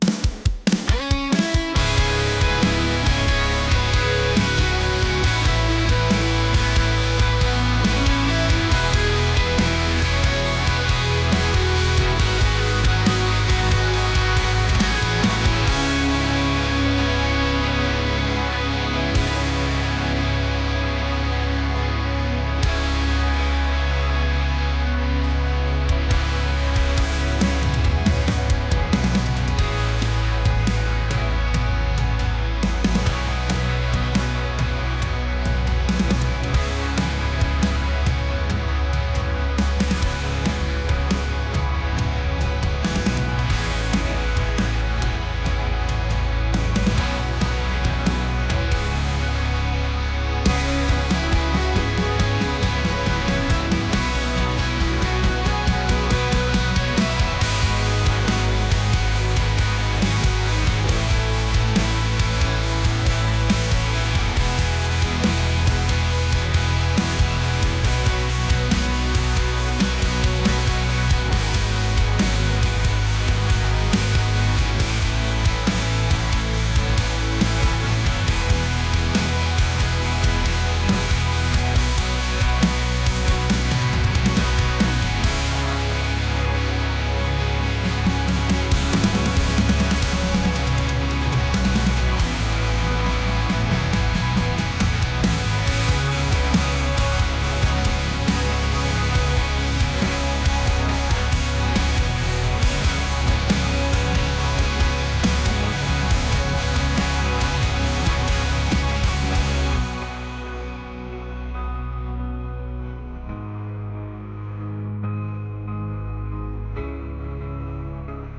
indie | rock | alternative